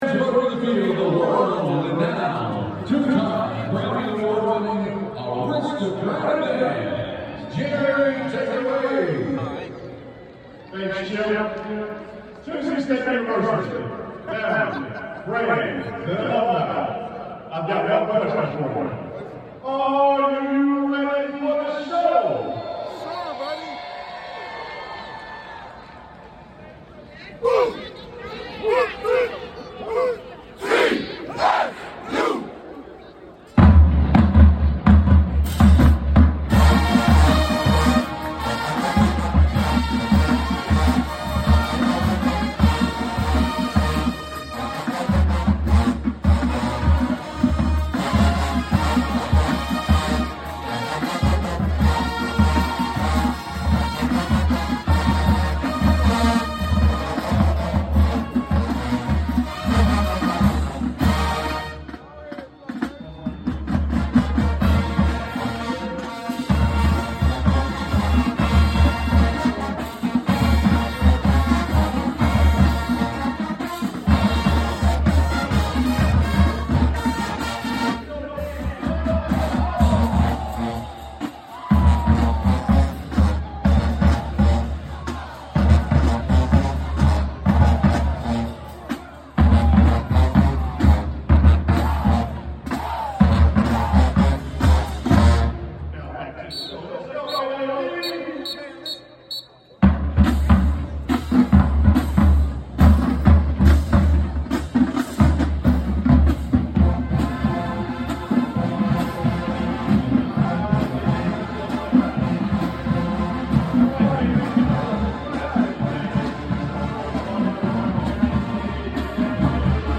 Tennessee State University Aristocrat of Bands Halftime Show at the Nissan Stadium.